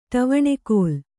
♪ ṭavaṇe kōl